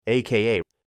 No inglês, a sigla é falada pronunciando as letras mesmo ou também falando o que ela significa.